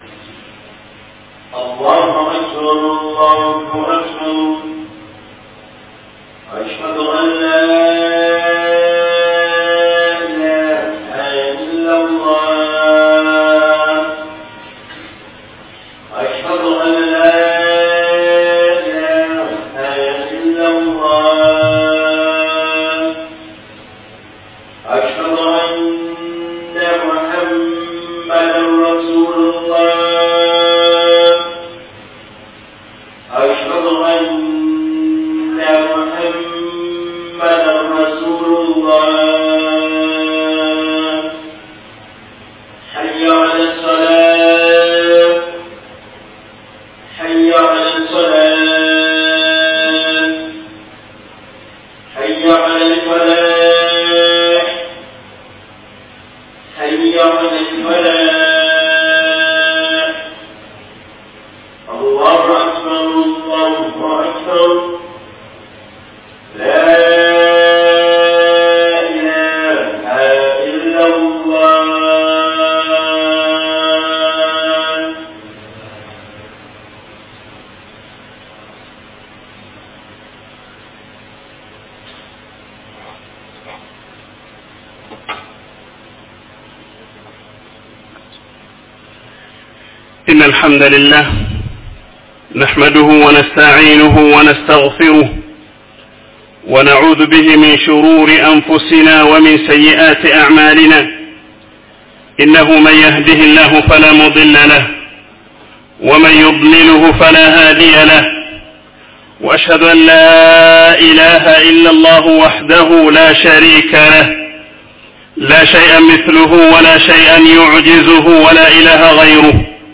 JUMMA KHUTUB
Nakasero Masjid Download Audio